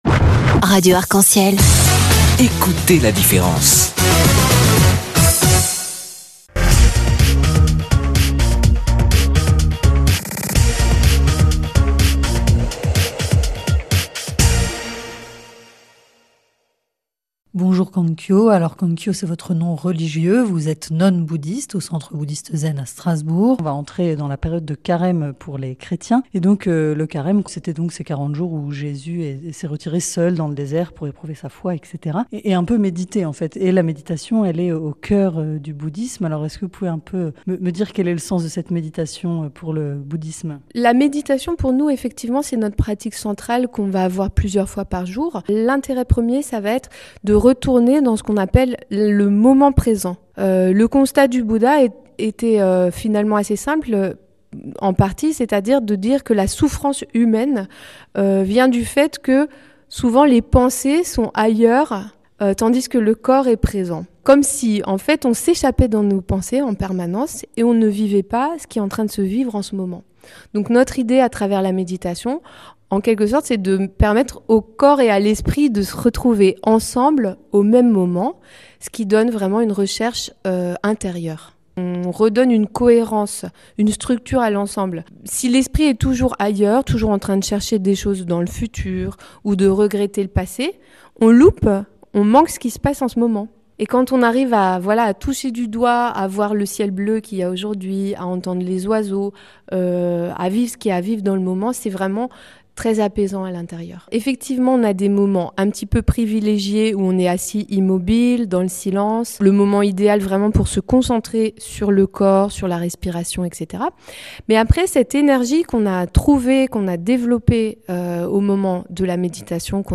INTERVIEW radio – février 2015 –